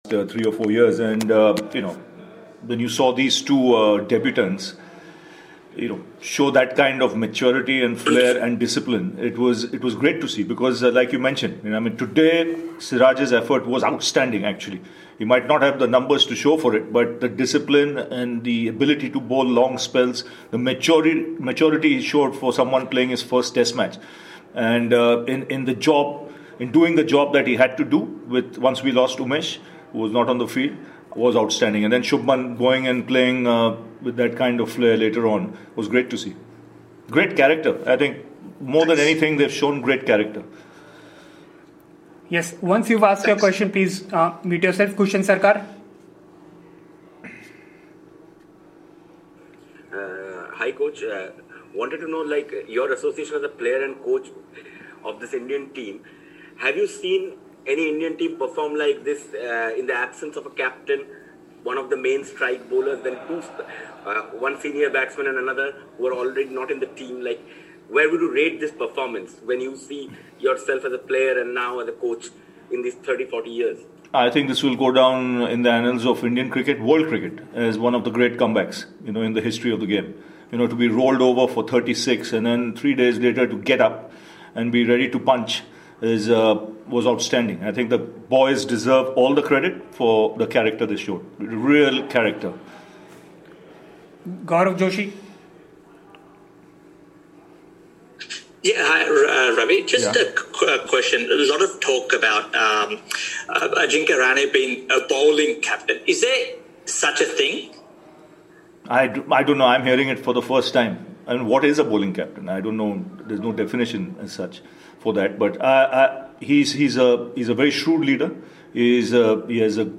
Ravi Shastri India's Head Coach addressed a virtual press conference after the 2nd Border-Gavaskar Test against Australia in Melbourne.